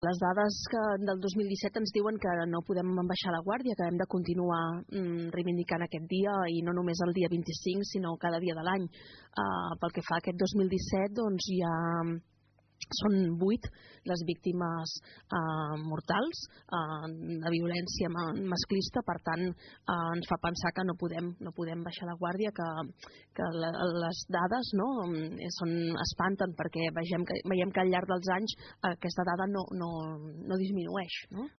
des del programa Palafolls en Xarxa es va organitzar un debat al voltant de la violència masclista i de la realitat social que envolta aquest tipus de violència